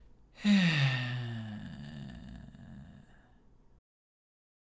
まずは、「え」か「あ」の言葉で大きくため息（声あり）をついて、伸ばした最後の小さい声の中に、緩いじりじり音が自然に混ざってこないか探します。
※見本音声(「え」の音)